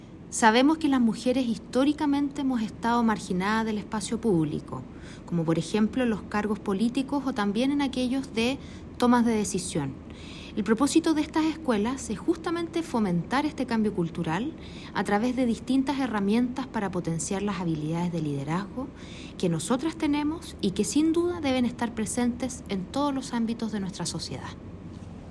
“Sabemos que las mujeres históricamente hemos estado marginadas del espacio público, como por ejemplo en los cargos políticos o en la toma de decisiones. El propósito de las escuelas es fomentar el cambio cultural a través de la entrega de herramientas para potenciar las habilidades de liderazgo que tenemos y que, sin duda, deben estar presente en todos los ámbitos de la sociedad”, afirmó la Directora Nacional del SernamEG, Priscilla Carrasco Pizarro.